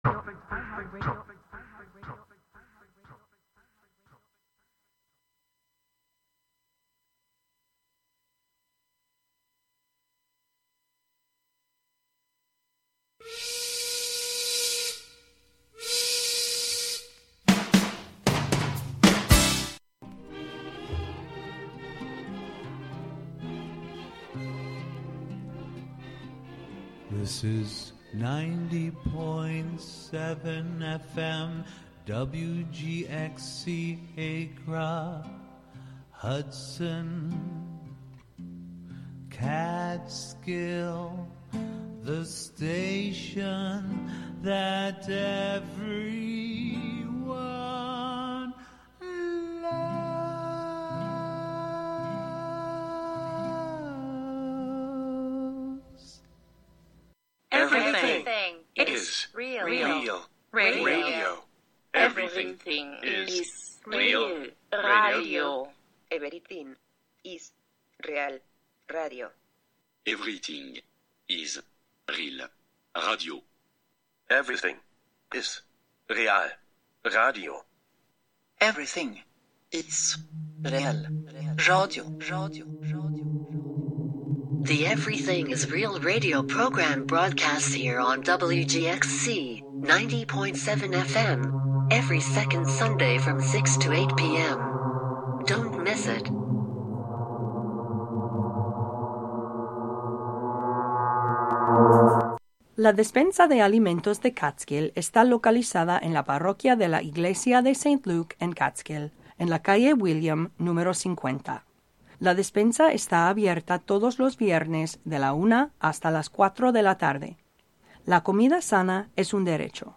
Tune in to this quarterly broadcast composed of local sound ecology, observational narration, and articulations of the mechanical components of the M49; its meanings and purpose reshaped as it traversed the machine of the road to arrive at its site in the forest of Wave Farm.